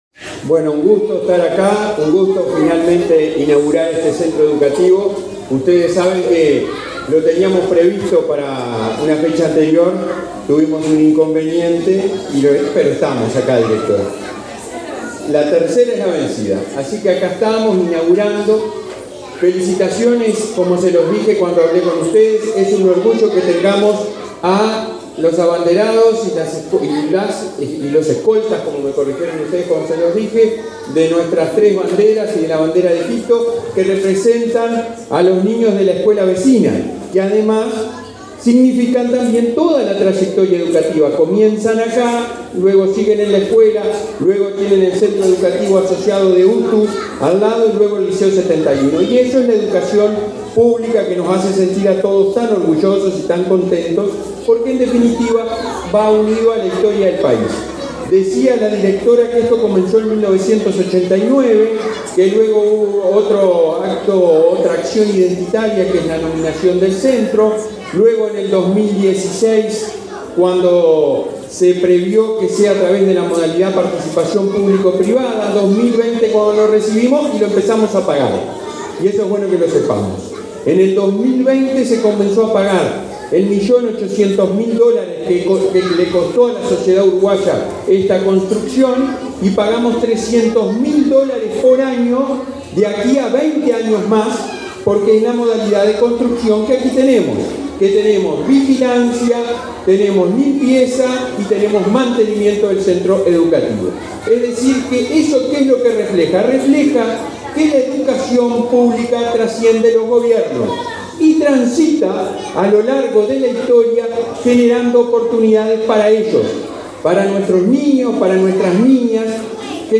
Palabras del presidente de ANEP, Robert Silva
Palabras del presidente de ANEP, Robert Silva 29/09/2023 Compartir Facebook X Copiar enlace WhatsApp LinkedIn El presidente de la Administración Nacional de Educación Pública (ANEP), Robert Silva, participó en la inauguración de dos jardines de infantes, este viernes 29 en Montevideo. En el centro n.° 306, Micaela Guyunusa, destacó la importancia de estos nuevos establecimientos.